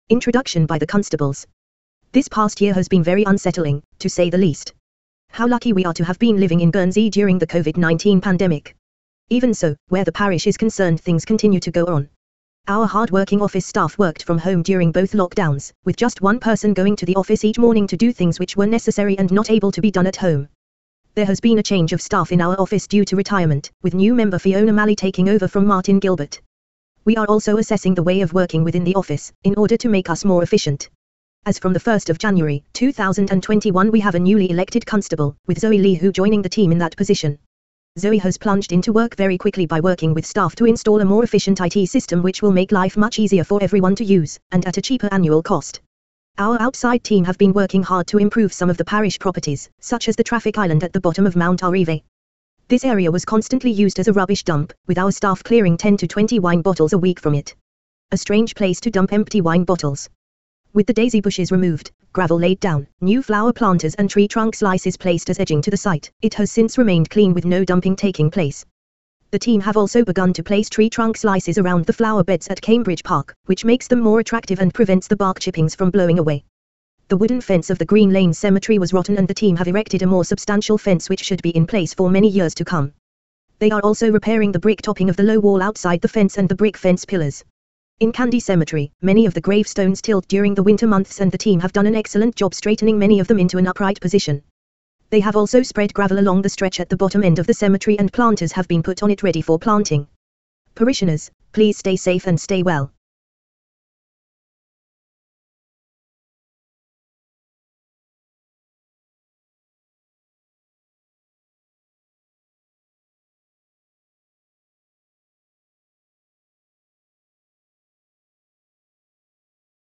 02-townie-15-intoroduction-by-the-constables.mp3